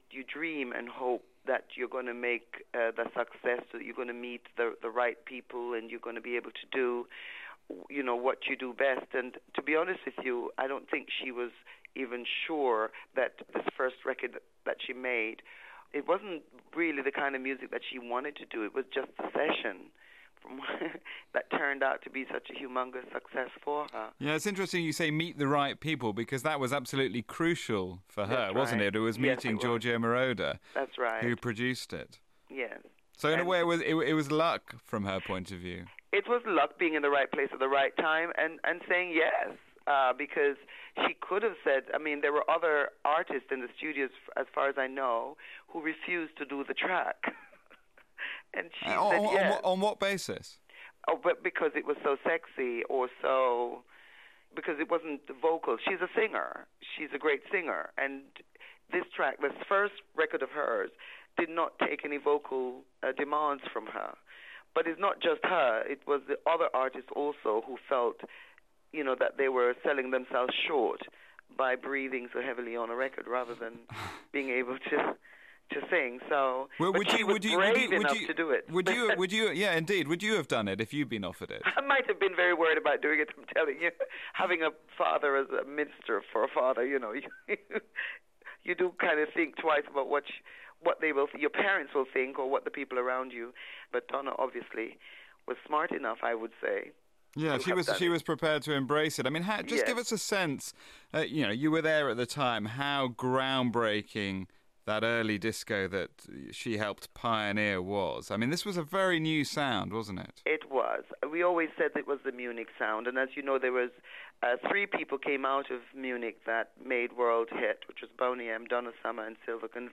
Former lead singer of Boney M talks about her memories of the Queen of Disco